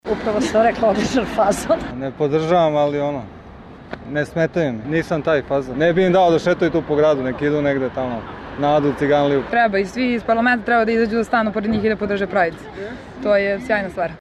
Građani o performansu